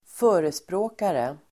Uttal: [²f'ö:resprå:kare]